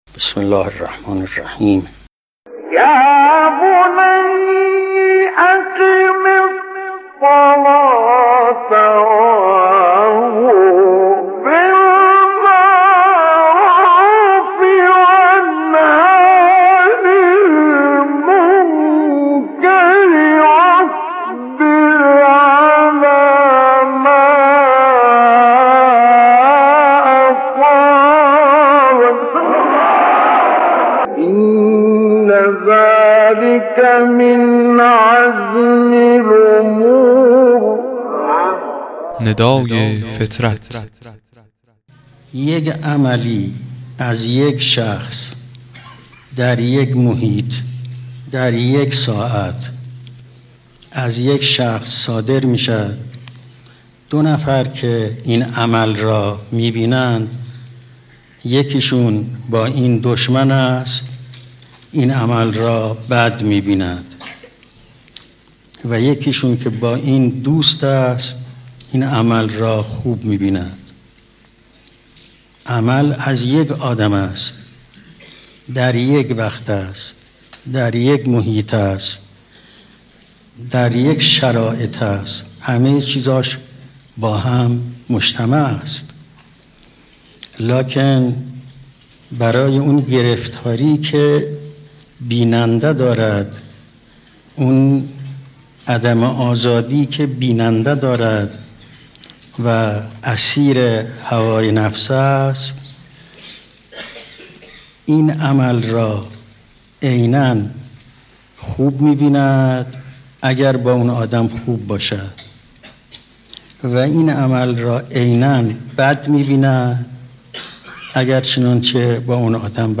قطعه کوتاه و بسیار زیبای صوتی از امام خمینی ره در ارتباط با تاثیر هوای نفس در تعقل انسان